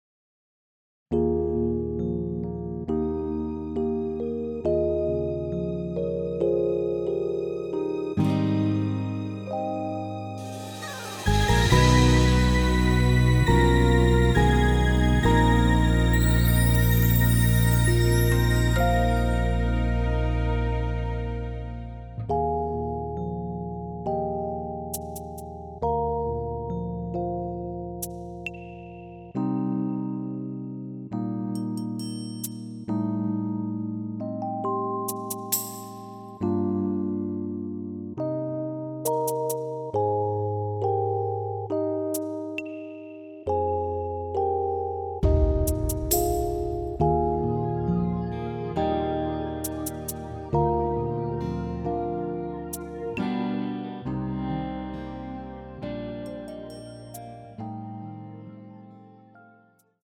원키에서(-5)내린 MR입니다.
Eb
앞부분30초, 뒷부분30초씩 편집해서 올려 드리고 있습니다.